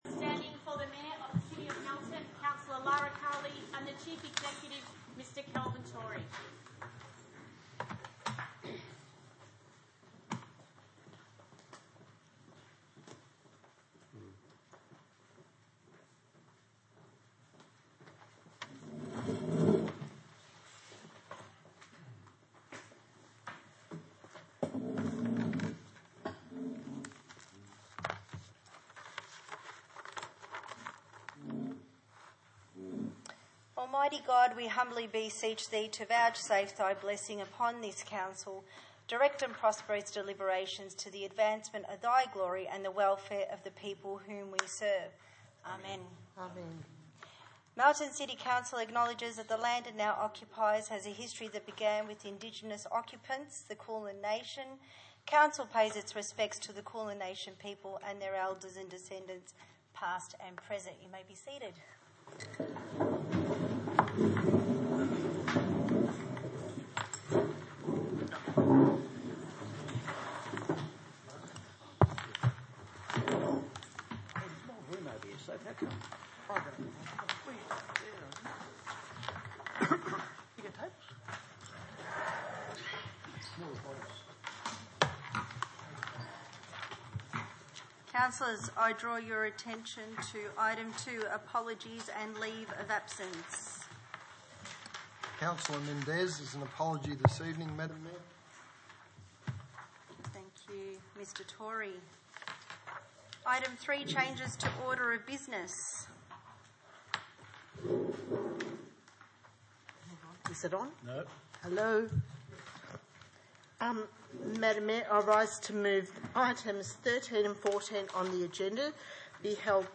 Ordinary Meeting 2 March 2020
Burnside Community Hall, 23 Lexington Drive, Burnside View Map